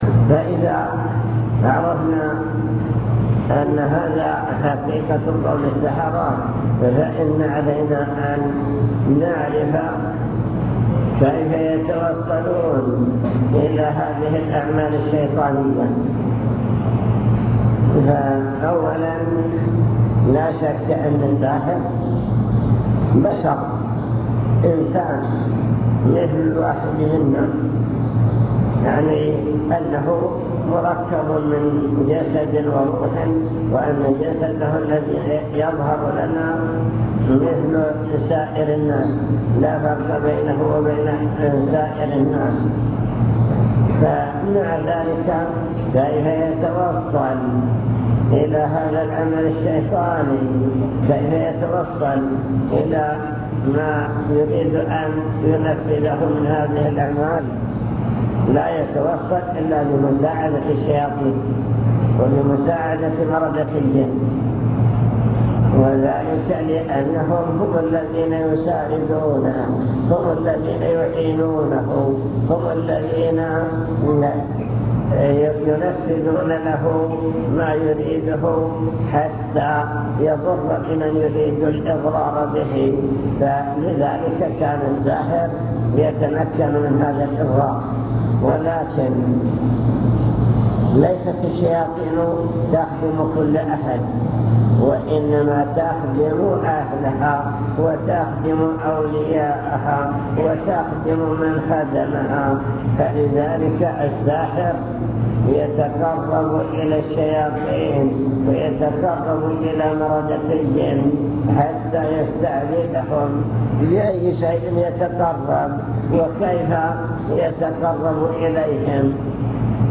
المكتبة الصوتية  تسجيلات - محاضرات ودروس  محاضرة بعنوان السحر وأثره في العقيدة